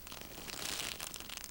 gravel.mp3